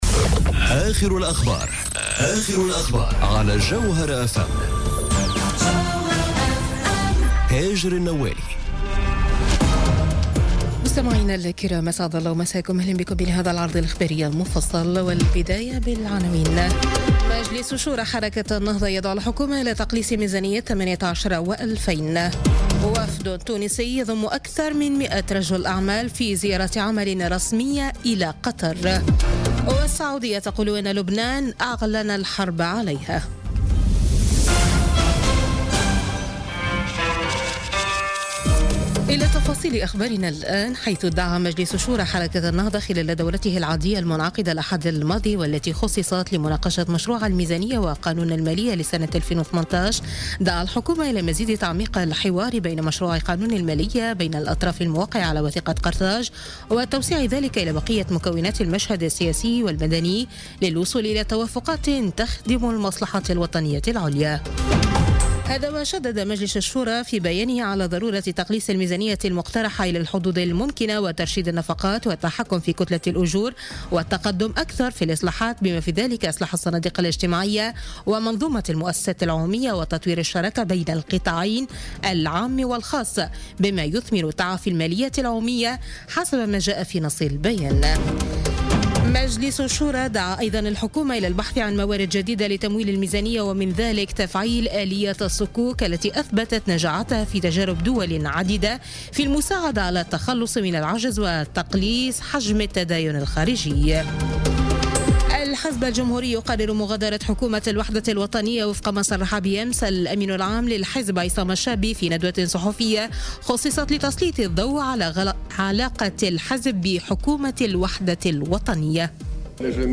نشرة أخبار منتصف الليل ليوم الثلاثاء 07 نوفمبر 2017